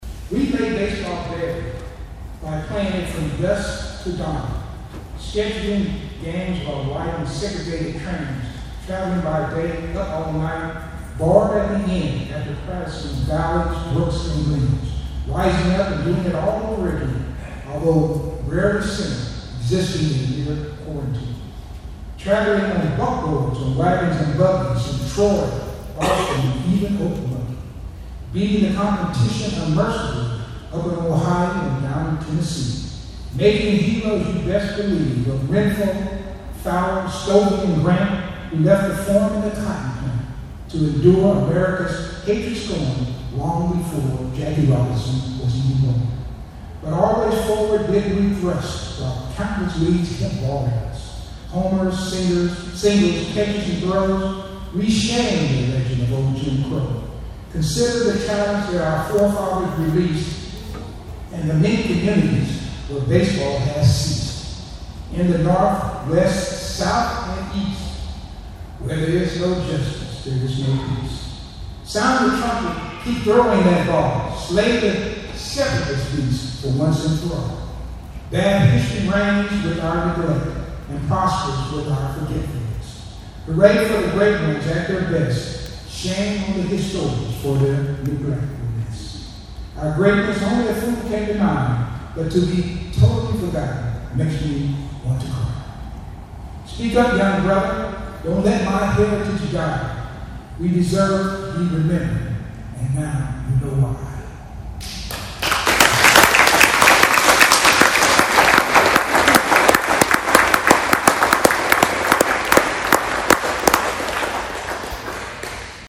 The Negro Leagues and its ties to the Manhattan area were featured as part of the kickoff to Juneteenth weekend festivities Thursday night at the Douglass Recreation Center.